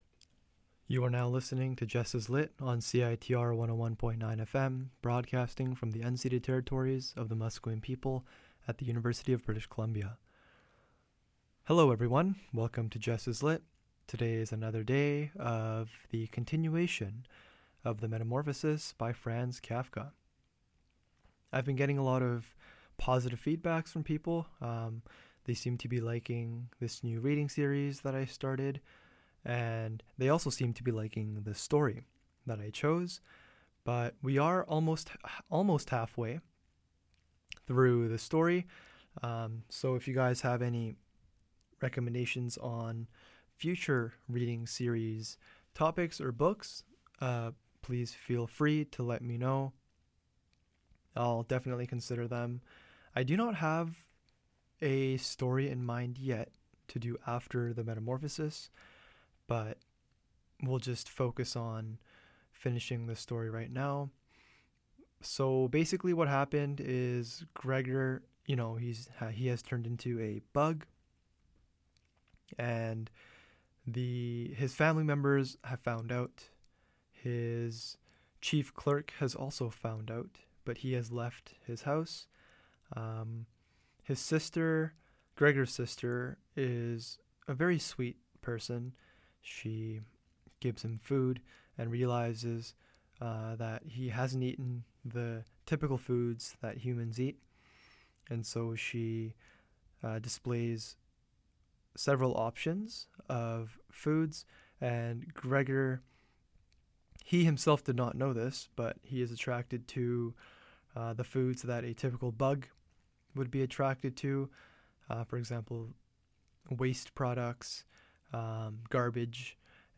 This episode is the continuation of the readings series: The Metamorphosis by Franz Kafka.